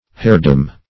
heirdom - definition of heirdom - synonyms, pronunciation, spelling from Free Dictionary
Heirdom \Heir"dom\, n. The state of an heir; succession by inheritance.